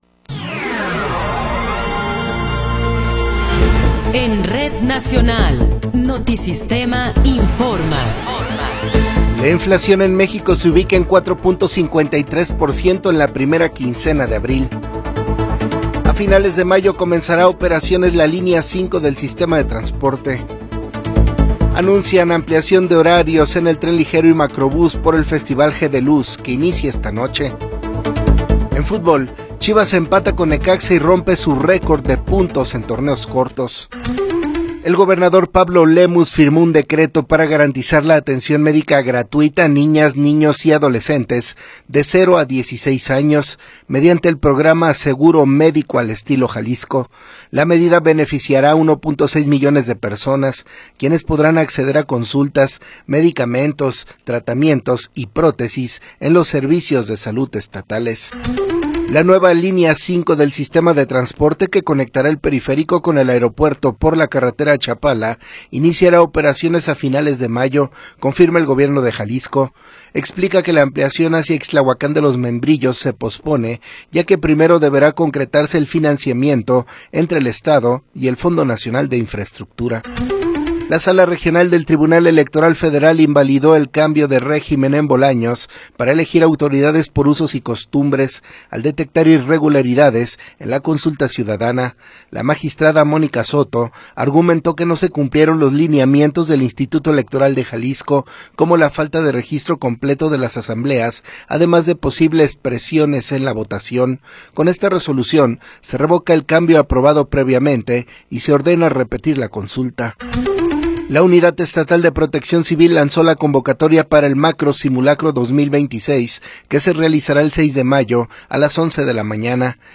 Noticiero 9 hrs. – 23 de Abril de 2026
Resumen informativo Notisistema, la mejor y más completa información cada hora en la hora.